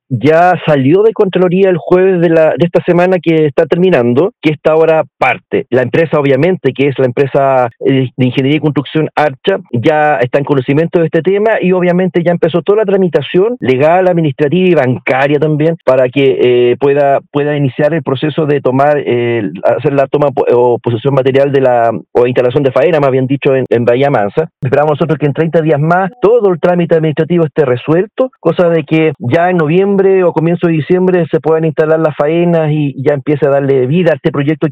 La iniciativa fue anunciada por el seremi de Obras Públicas en Los Lagos, Juan Fernando Alvarado, quien detalló que las obras tendrán un plazo de ejecución de 570 días.